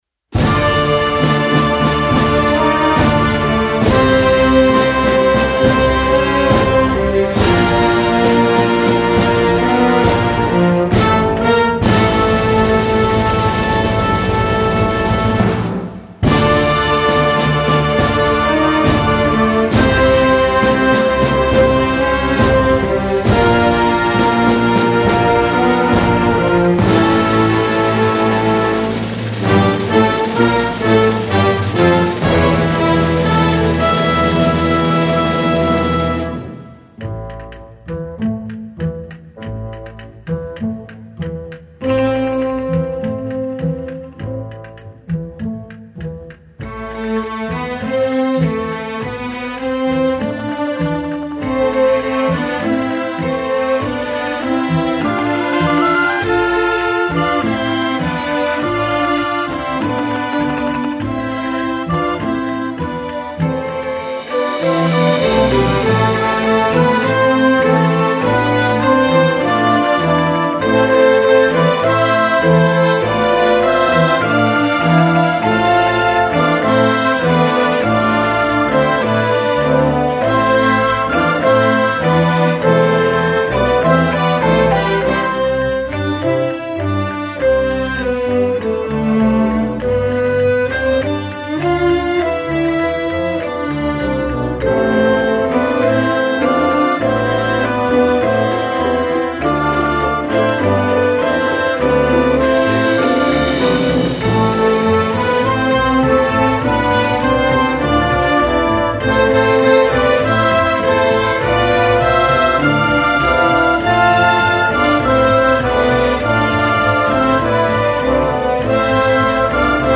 Voicing: Full Orchestra